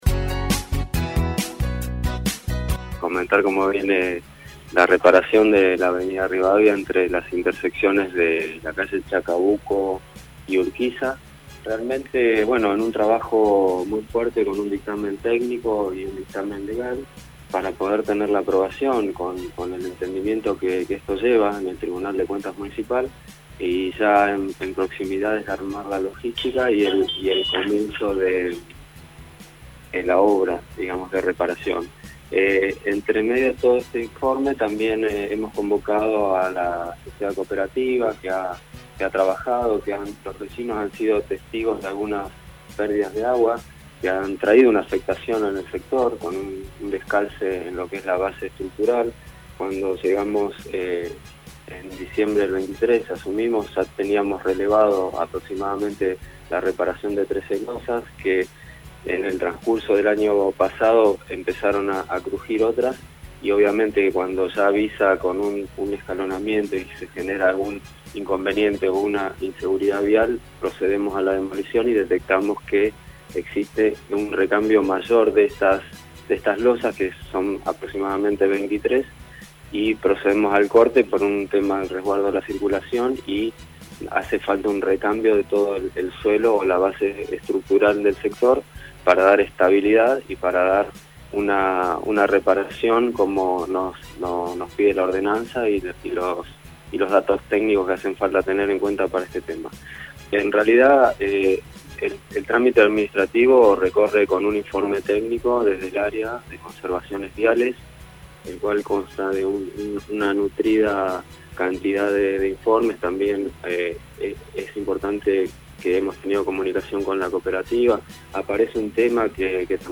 Se convocó a una conferencia de prensa de último momento por parte de la Municipalidad para dar cuenta de la situación de la obra de reparación de un tramo de la Avenida Rivadavia entre Chacabuco y Urquiza.
Esto explicaba el Secretario de Infraestructura y obra pública, Luis Romero: